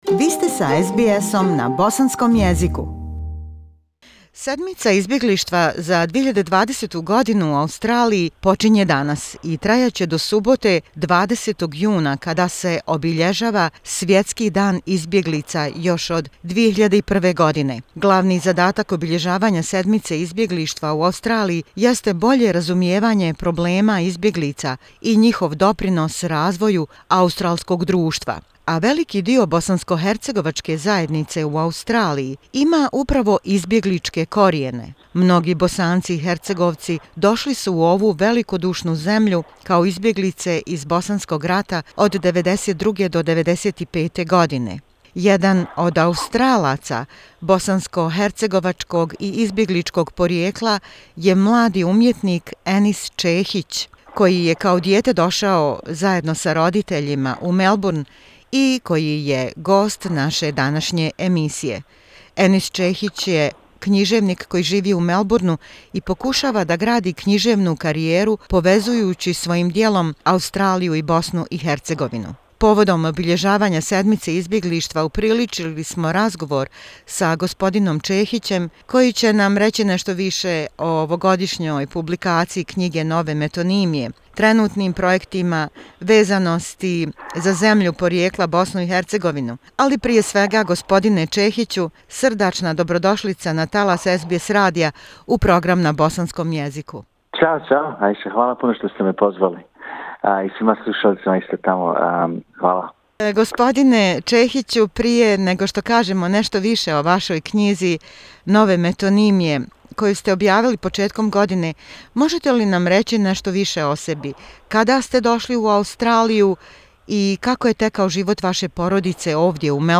Marking The Refugees Week: an interview with Young Australian writer with Bosnian and refugee background